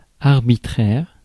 Ääntäminen
Synonyymit autocratique Ääntäminen France: IPA: /aʁ.bit.ʁɛʁ/ Haettu sana löytyi näillä lähdekielillä: ranska Käännös Ääninäyte Adjektiivit 1. arbitrary US 2. random US Substantiivit 3. arbitrariness Suku: f .